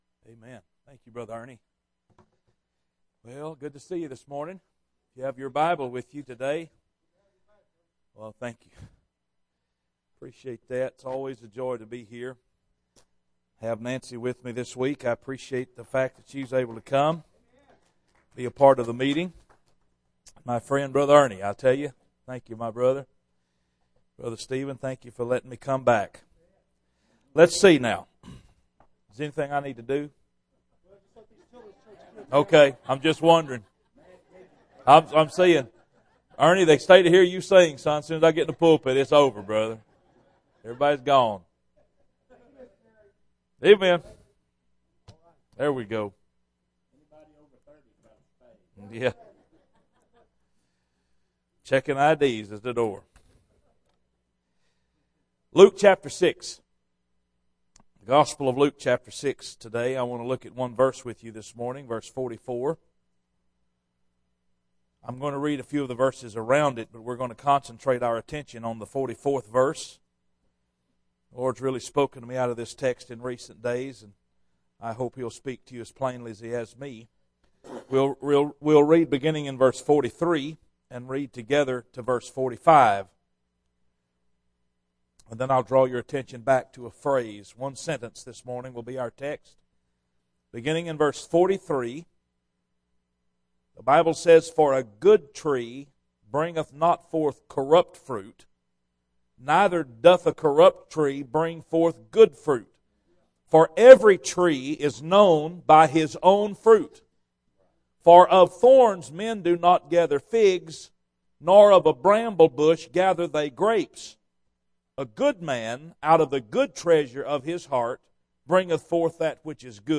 Bible Text: Luke 6:43-45 | Preacher